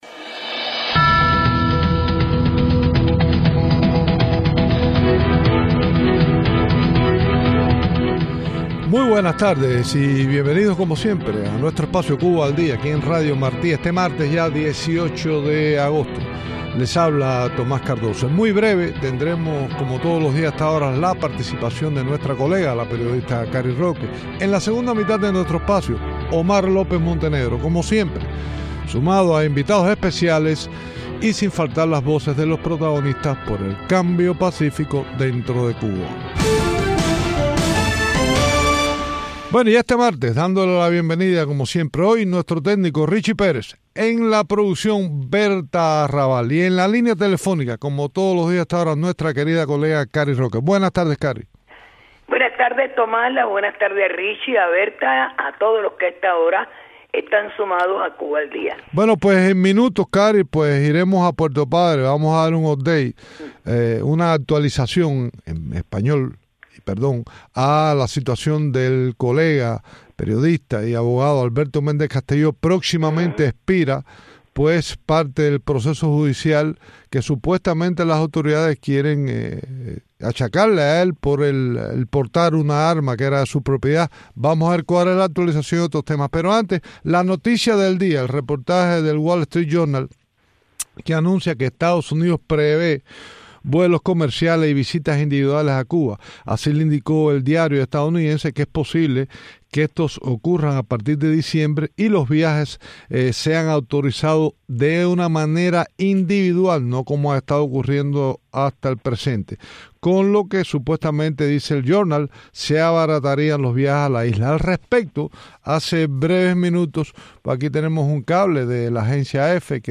Entrevistas desde Cuba